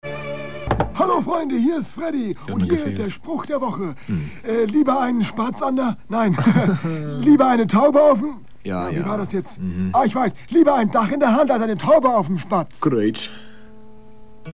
aus den SWF3 Sendungen vor über 40 Jahren aufgenommene Mitschnitte, digitalisiert und hier nun veröffentlicht.
Etwa alle 2 - 3 Wochen nach dem Samstag Mittagessen schaltete man das Radio an und nahm diese Sendungen auf MC auf.